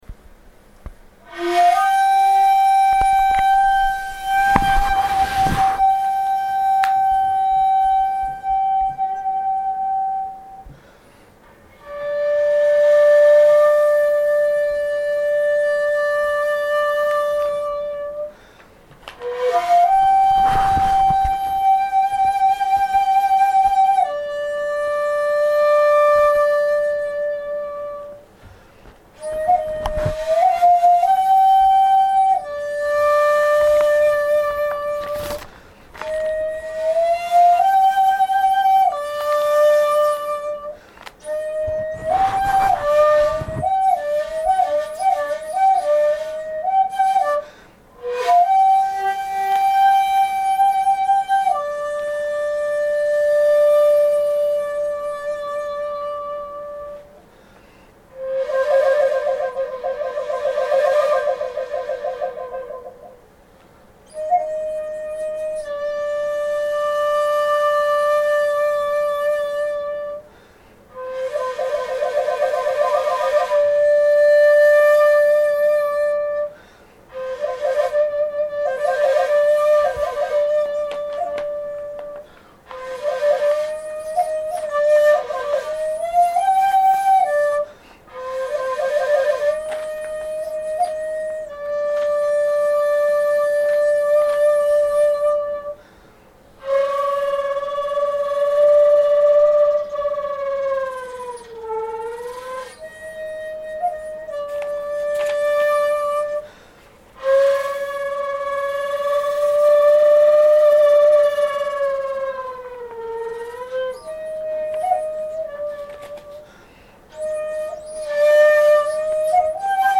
私も貴志道場門下生として、ホームコンサートを聴かせていただきました。